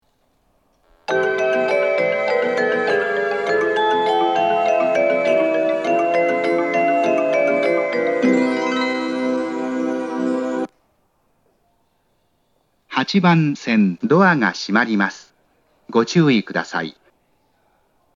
発車メロディー
余韻切りです。5番線と同様、余韻切りが多いです。
まさかこのホームで打ち返しされるとは・・・。